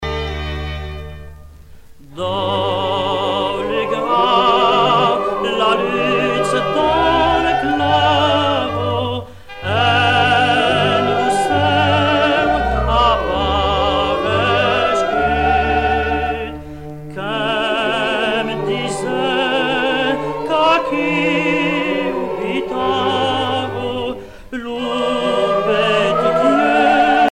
circonstance : Noël, Nativité
Genre strophique
Pièce musicale éditée